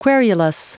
Prononciation du mot querulous en anglais (fichier audio)